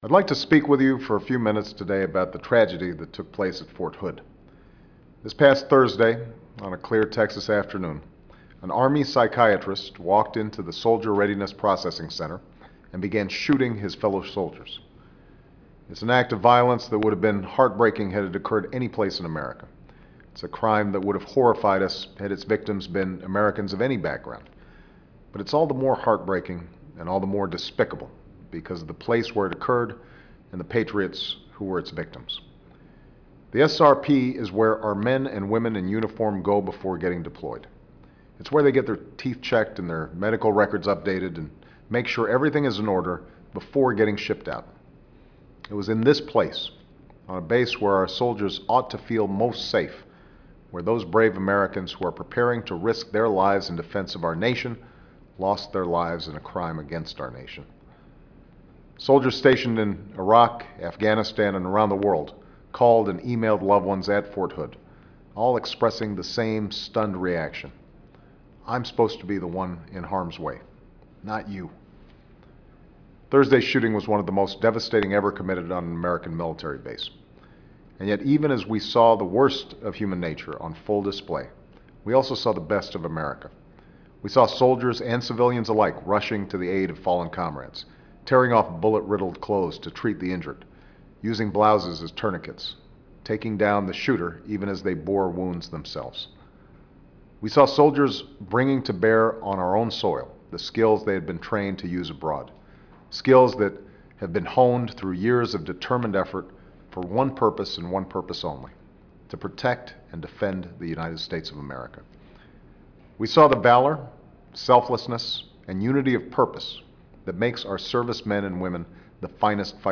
Barack Obama's weekly radio addresses (and some other speeches).